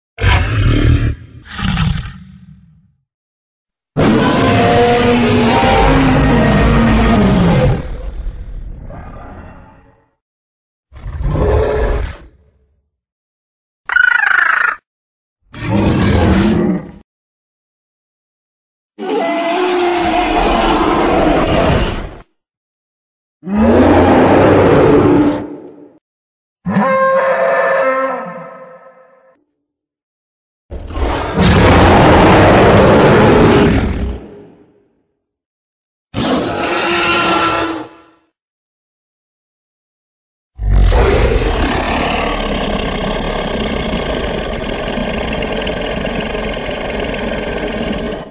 Dinosaurier klingelton kostenlos
Kategorien: Tierstimmen